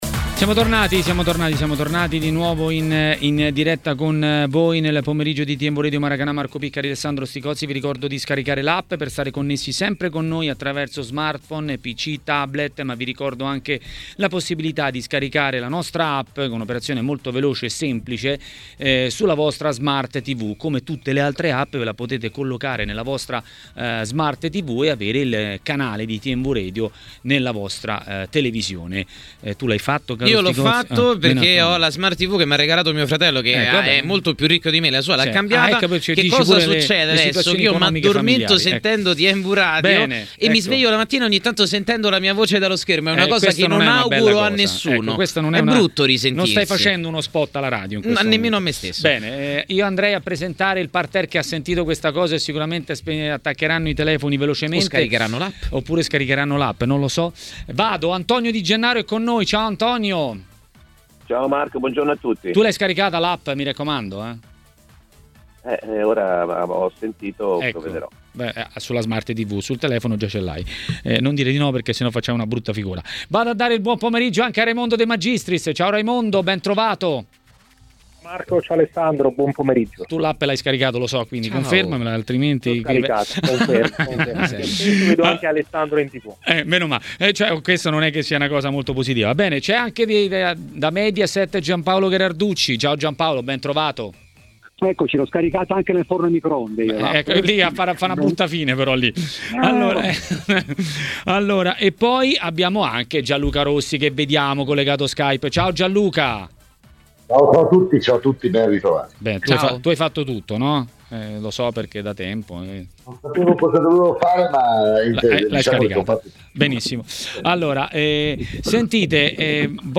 Antonio Di Gennaro, ex calciatore e commentatore tv, è intervenuto a TMW Radio, durante Maracanà, per parlare di Conte e non solo.